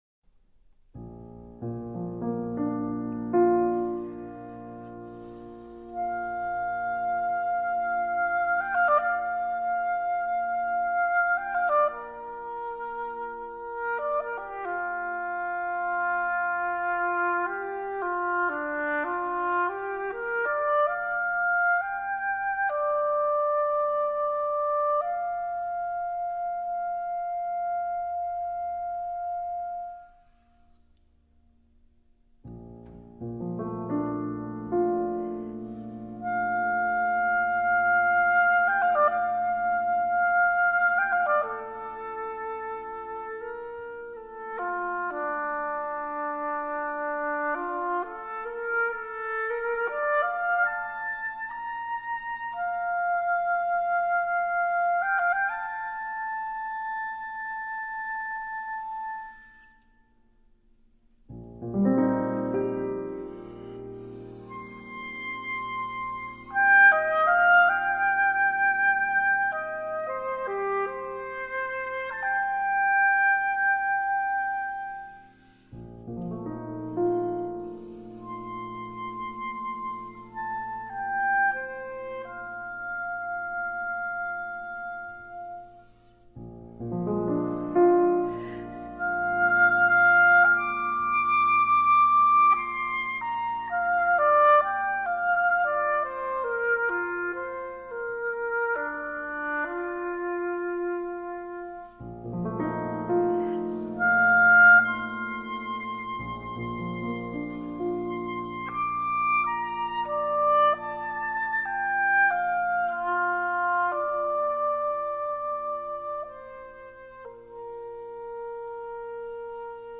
晚安曲分享的是張雙簧管與鋼琴，
心裡一跳，這Oboe好動人，質感又美。
這兩張是在同一段時間，於於德國斯圖加特錄音的。